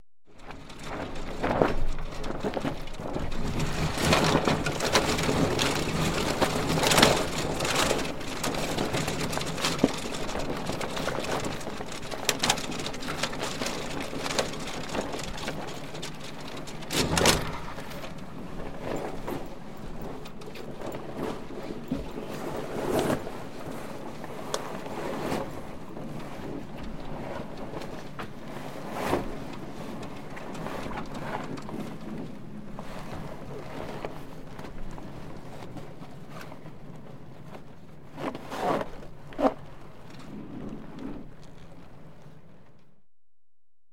Category: Sound FX   Right: Personal
Tags: Dungeons and Dragons Sea Ship